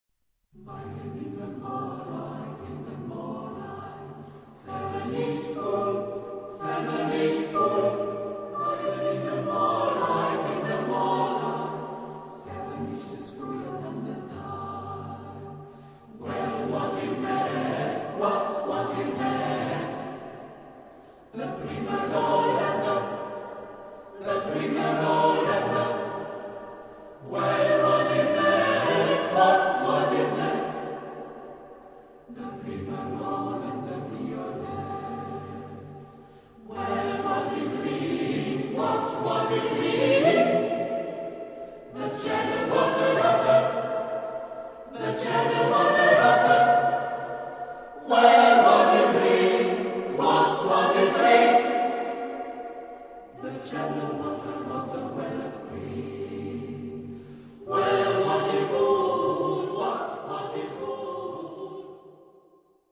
for unaccompanied voices
Genre-Style-Forme : Profane ; noël
Type de choeur : SSATB  (5 voix mixtes )
Tonalité : sol mineur